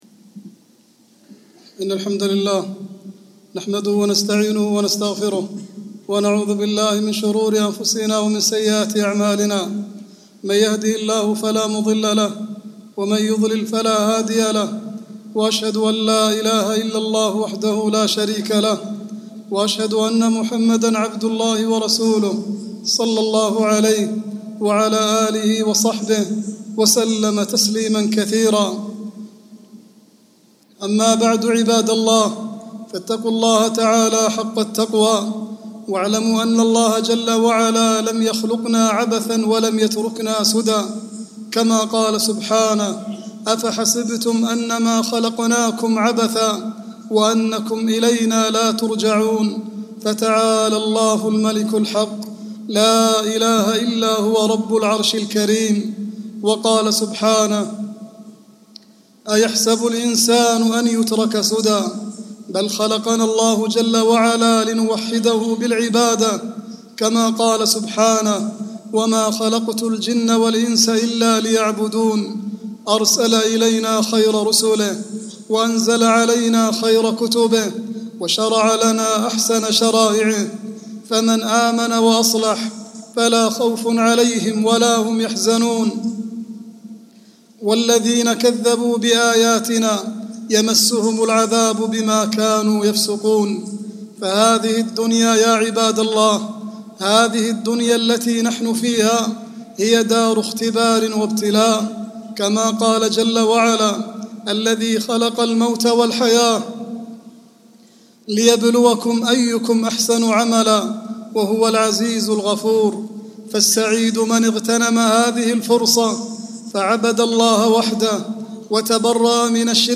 khuthah--28-oct-16.mp3